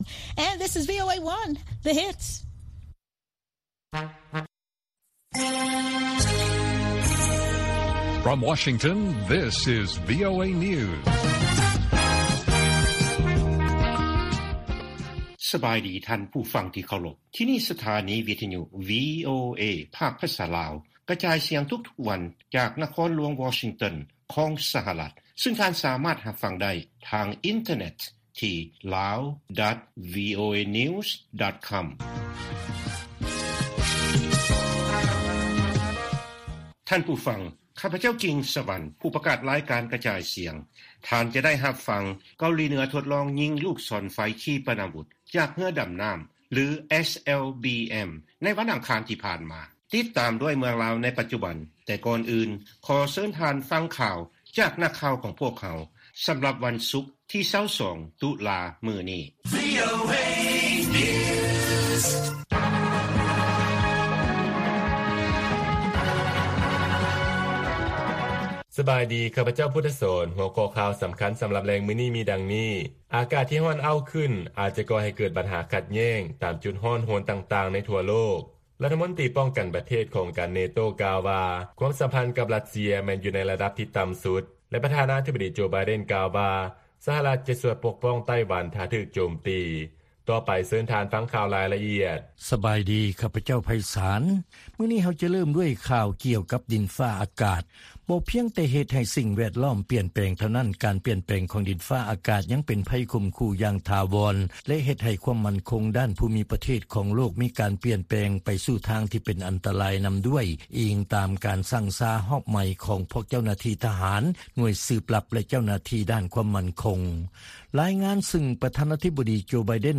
ວີໂອເອພາກພາສາລາວ ກະຈາຍສຽງທຸກໆວັນ. ຫົວຂໍ້ຂ່າວສໍາຄັນໃນມື້ນີ້ມີ: 1) ການເດີນລົດໄຟ ຂະບວນປະຖົມມະລືກ ເຊື່ອມຕໍ່ລະຫວ່າງເມືອງຄຸນໝິງກັບນະຄອນຫຼວງວຽງຈັນ ຈະດຳເນີນຕາມກຳນົດການທີ່ວາງໄວ້.